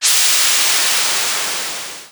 GasReleasing01.wav